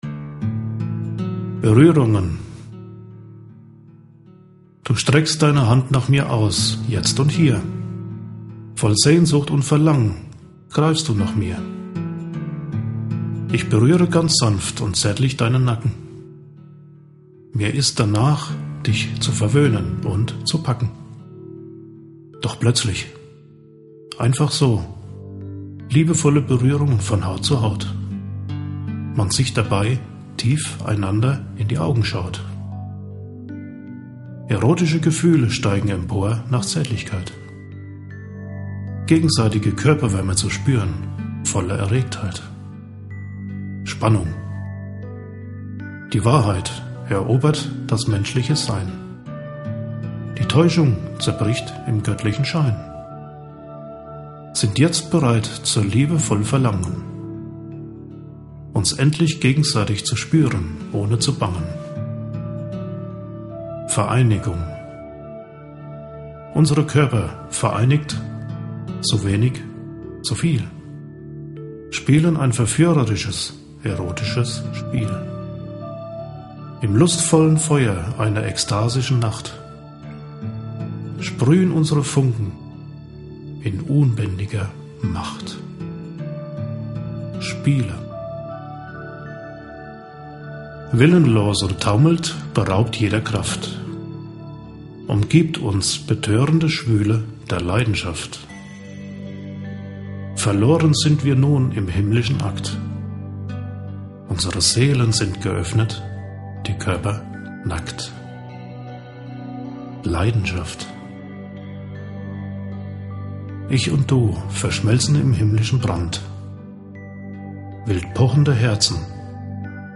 Der Klang seiner Stimme wirkt beruhigend und führt die Zuhörer in eine andere Welt voller Träume, Sehnsüchte und Verlangen nach Liebe.